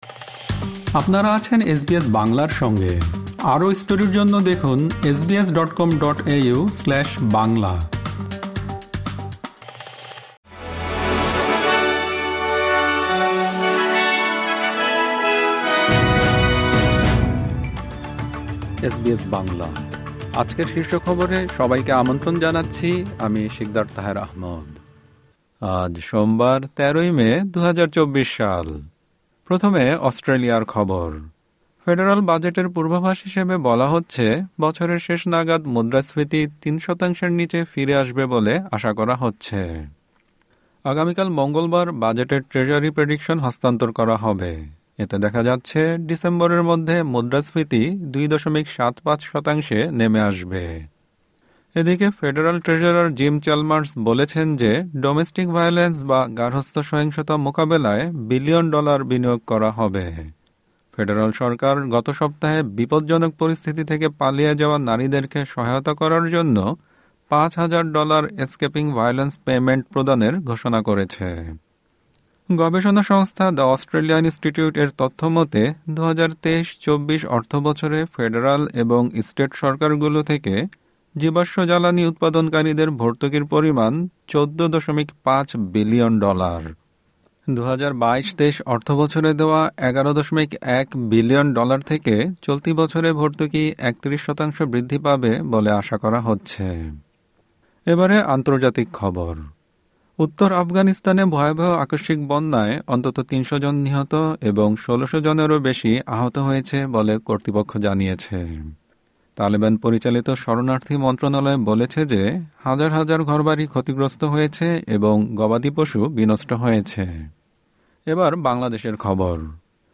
এসবিএস বাংলা শীর্ষ খবর: ১৩ মে, ২০২৪